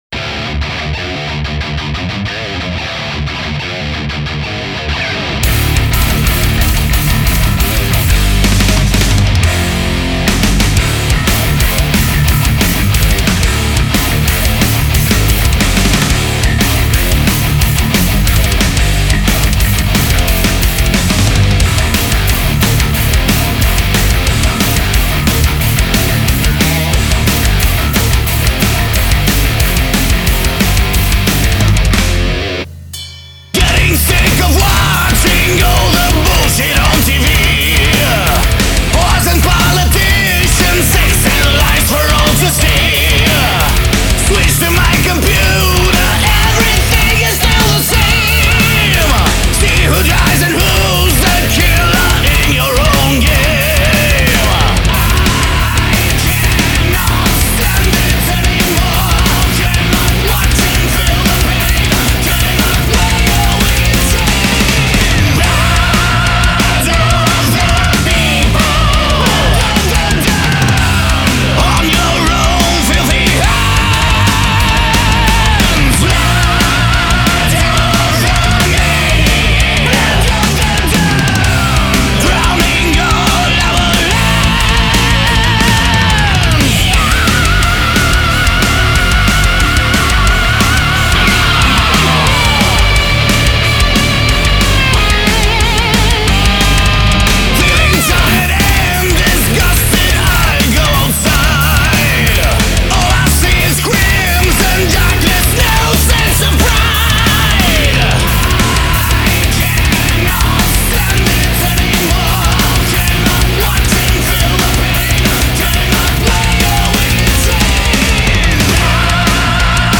Genre: Metal
pure Heavy Metal songs